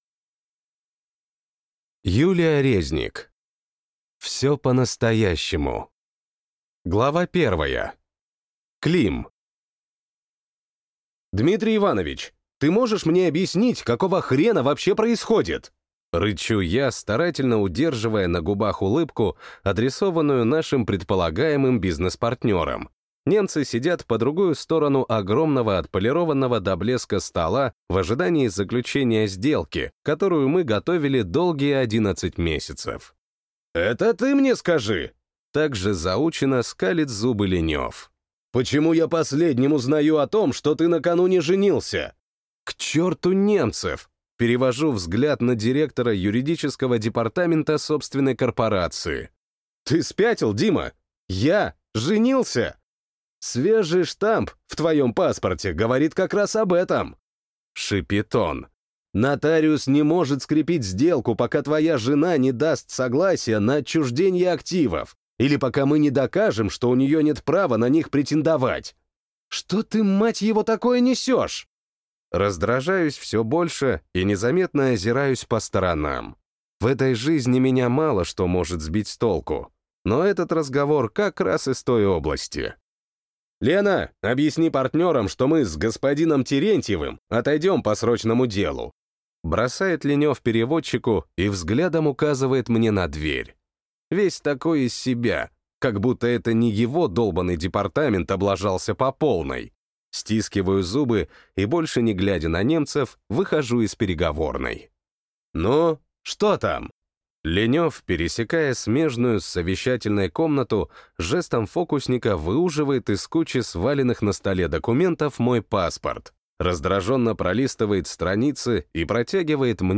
Аудиокнига Все по-настоящему | Библиотека аудиокниг
Прослушать и бесплатно скачать фрагмент аудиокниги